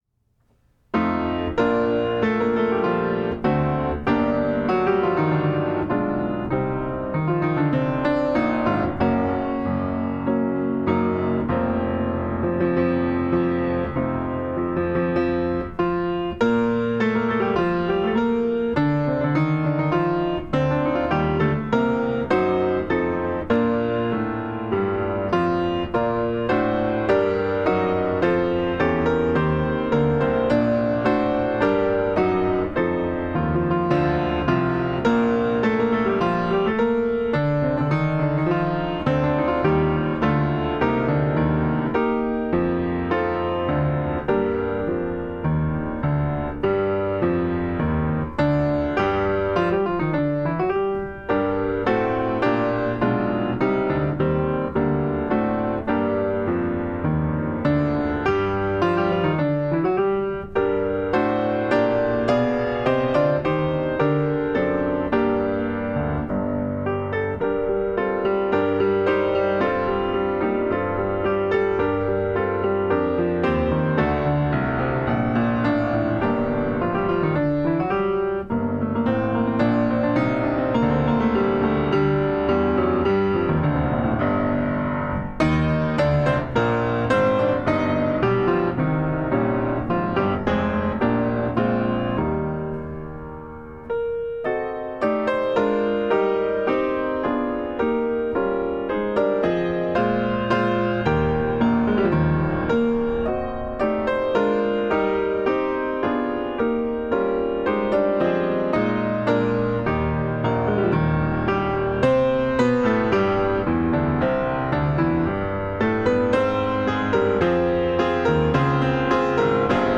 Service of Worship
Offertory